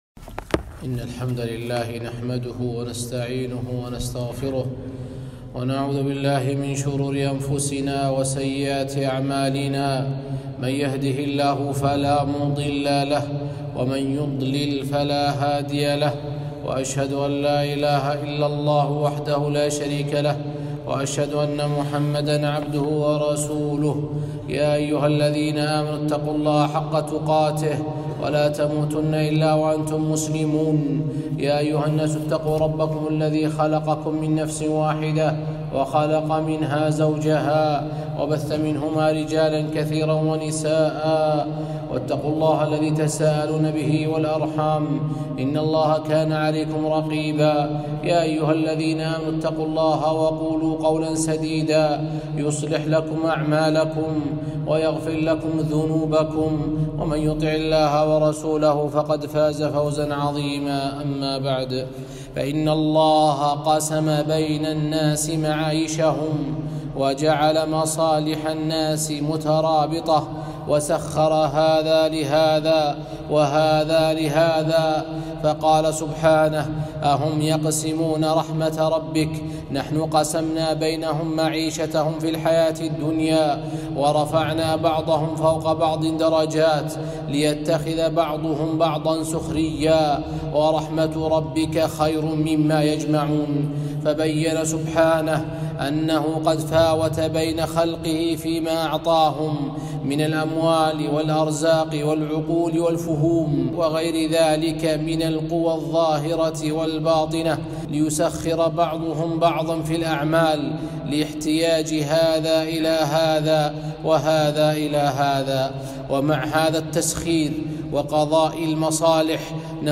خطبة - (فَلَا تَخْشَوُا النَّاسَ وَاخْشَوْنِ)